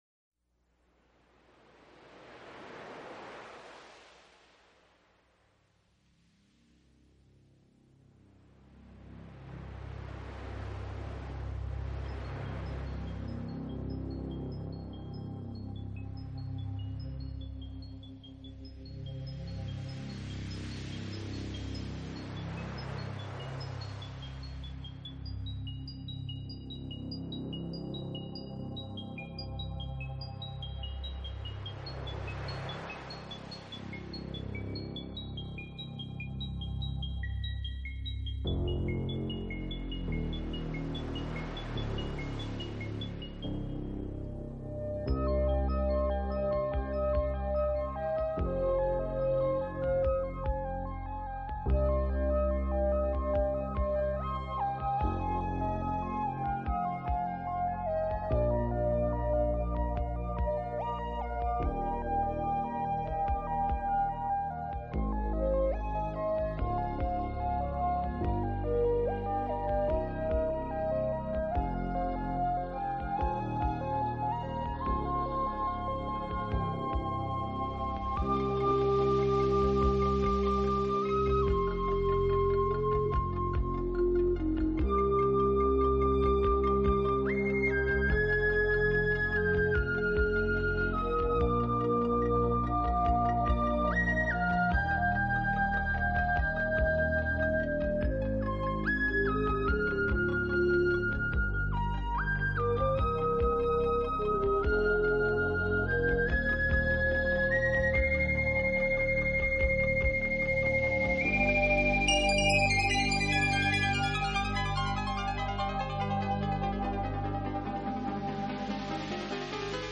纯乐钢琴
音乐类型: New Age